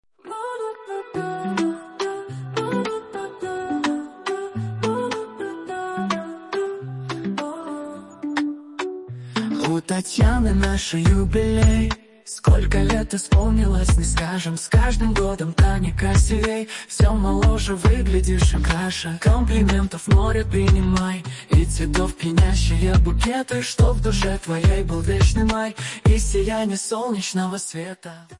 Фрагмент 2 варианта исполнения (мужским голосом):